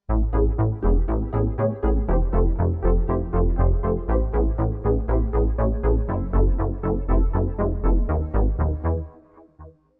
Emotional Quote TTS